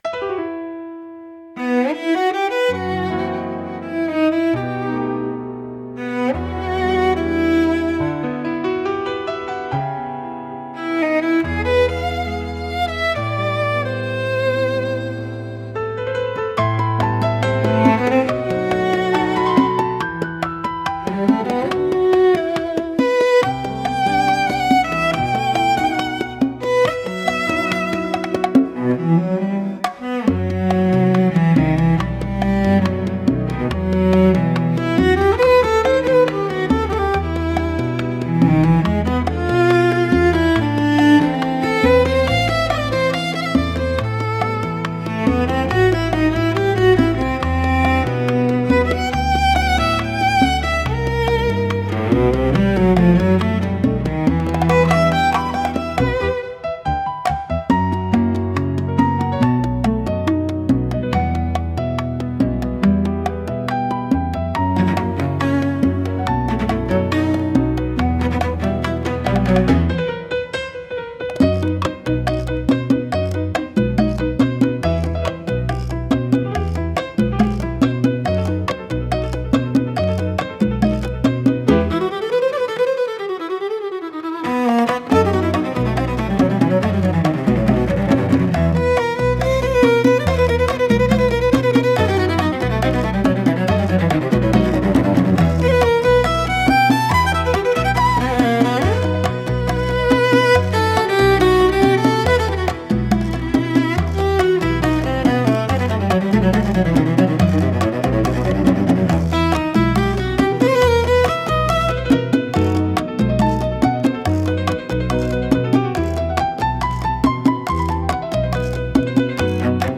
música e arranjo IA) instrumental 7